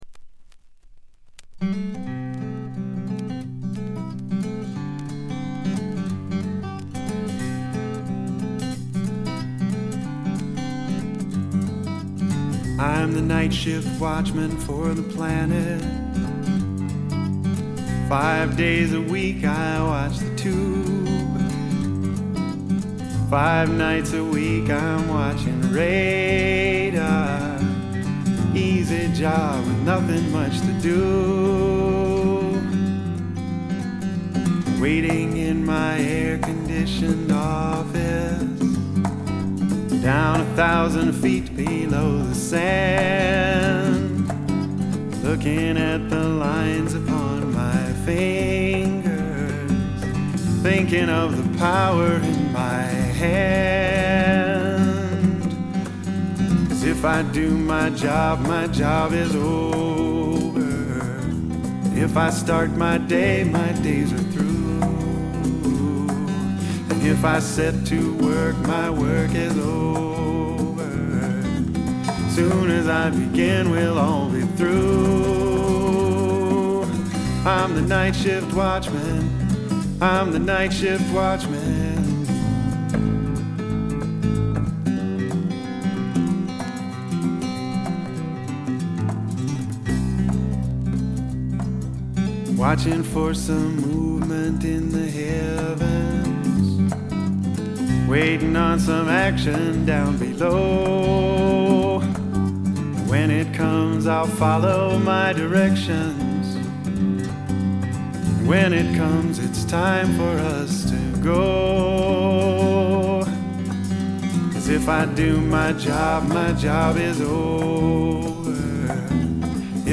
ちょっとメローなシンガー・ソングライターがお好きな方にはどんぴしゃでハマると思います。
試聴曲は現品からの取り込み音源です。
Guitar, Vocals